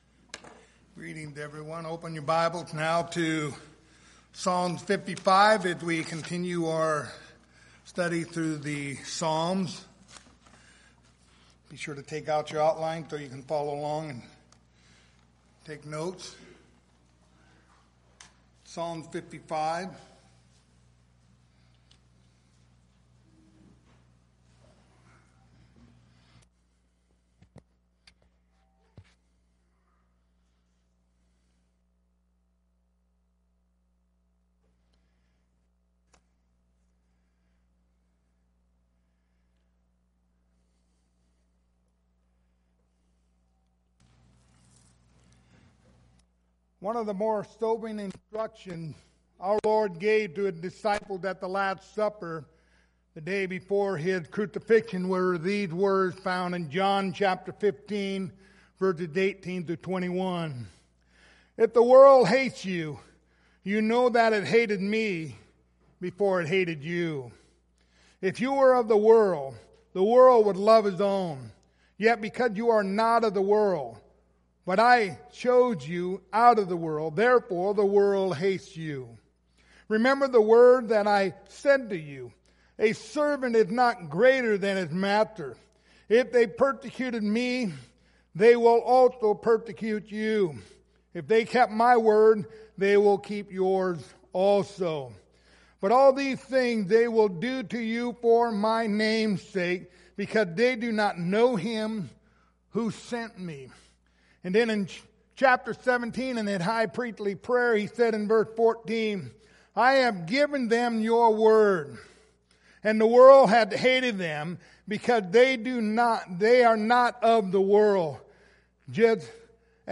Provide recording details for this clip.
The book of Psalms Passage: Psalms 55:1-23 Service Type: Sunday Morning Topics